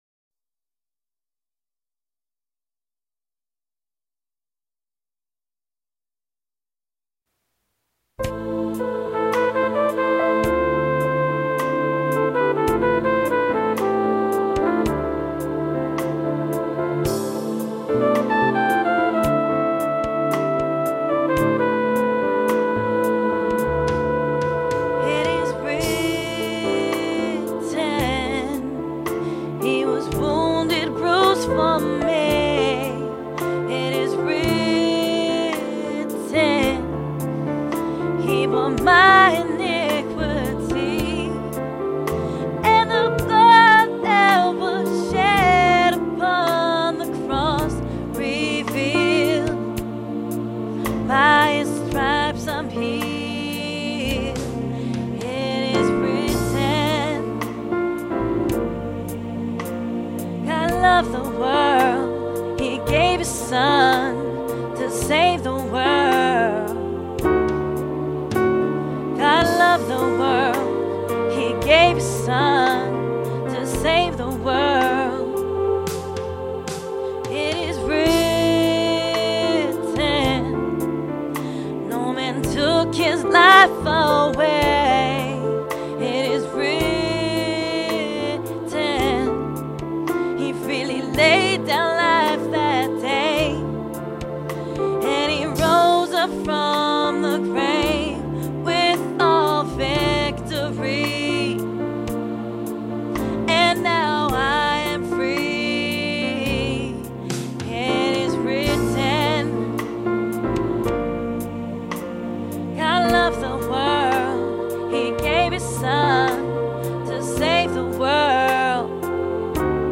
our most recent effort is a vocal work